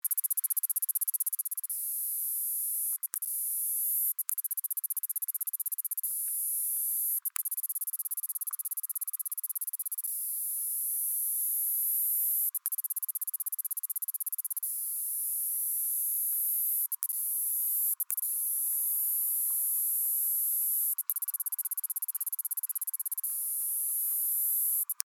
Chlorobalius_leucoviridis_mimicry2.ogg.mp3